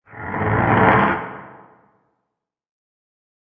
descargar sonido mp3 arrastrando